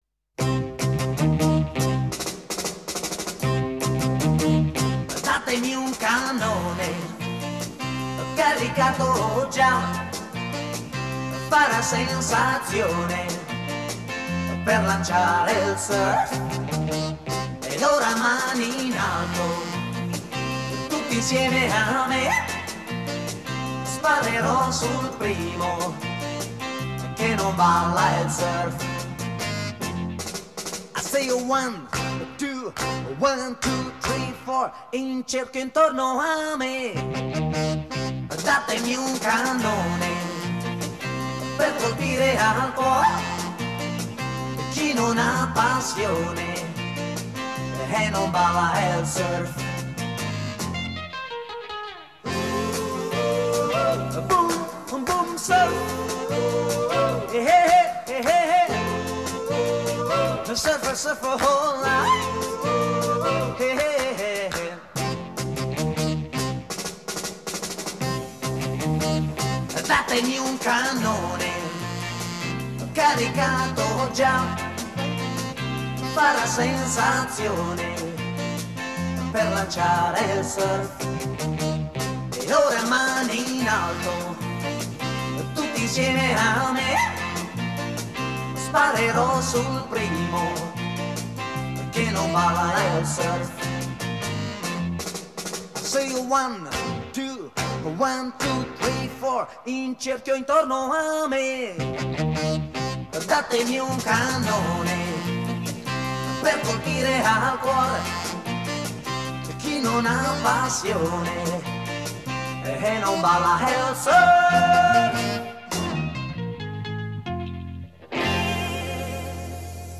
Несколько версий классических твистов: